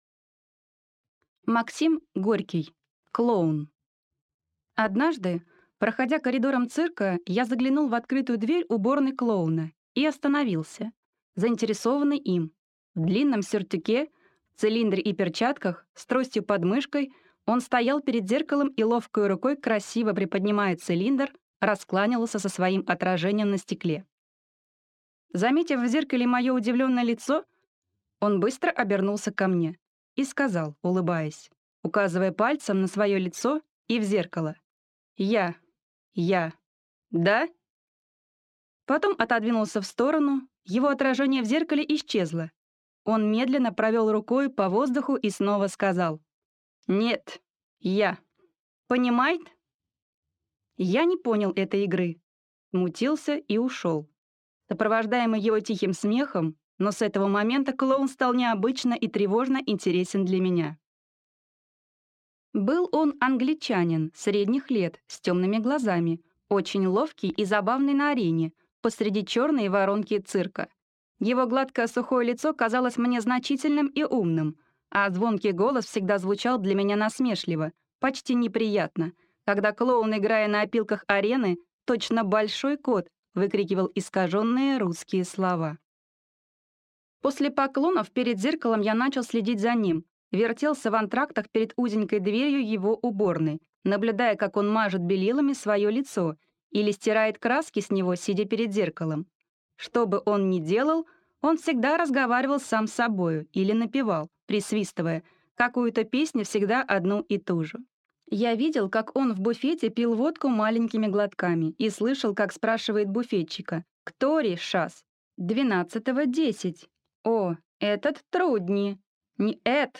Аудиокнига Клоун | Библиотека аудиокниг